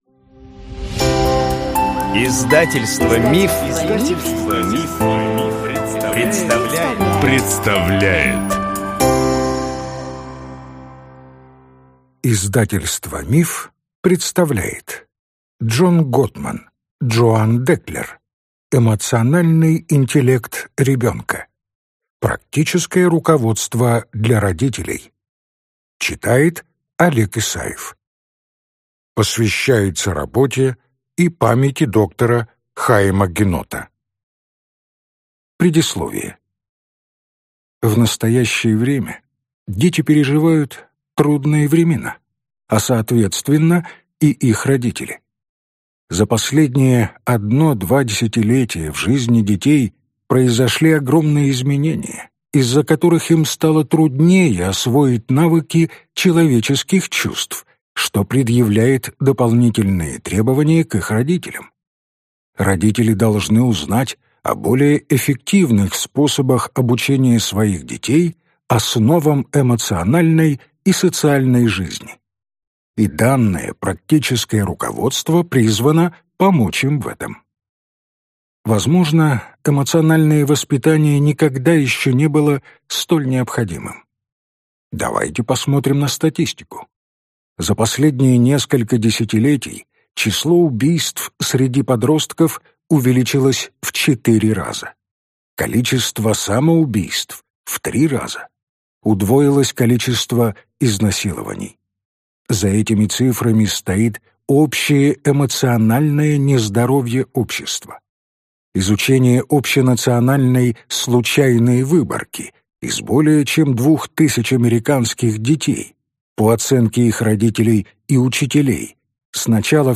Аудиокнига Эмоциональный интеллект ребенка | Библиотека аудиокниг